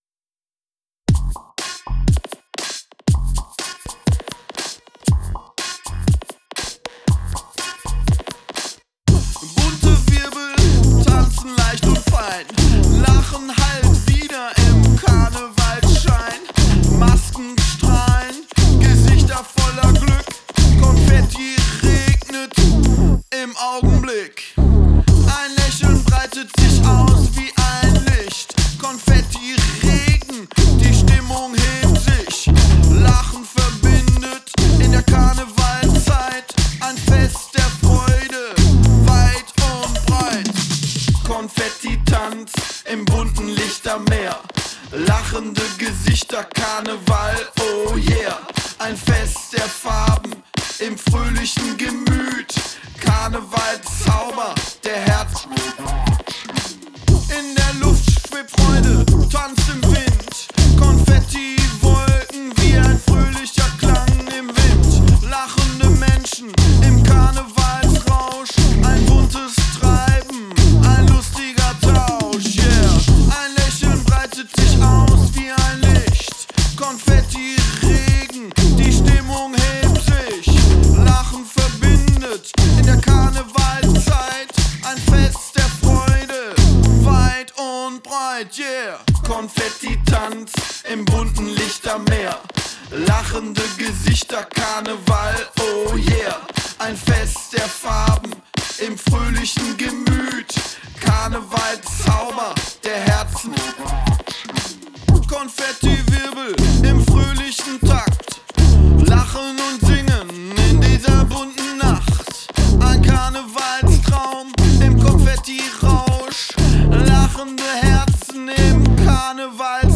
Hi, klingt eigentlich ganz ordentlich (hier auf dem iphone, hehehe ;-) nur irgendwas mit den „s“ und „f“ finde ich manchmal komisch, und die Instrumentierung ist relativ unbuisy.
So hier habe ich mal was neues versucht ist wohl ein Trick für Bass und Kick Verhältnis und um die Kick fetter zu bekommen. Ist glaube ich wieder einen Ticken Zuviel dieser Plannar neigt zum reindrehen.